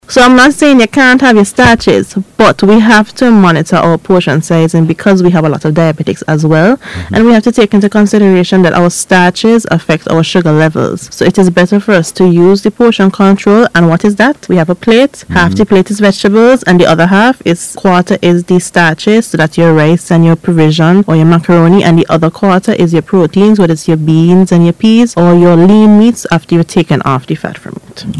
Speaking on a recent radio program, she emphasized that adopting proper portion sizes and maintaining a balanced diet are essential steps in reducing the risk of chronic diseases and improving overall public health.